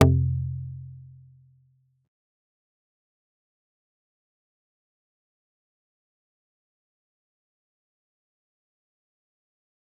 G_Kalimba-F2-pp.wav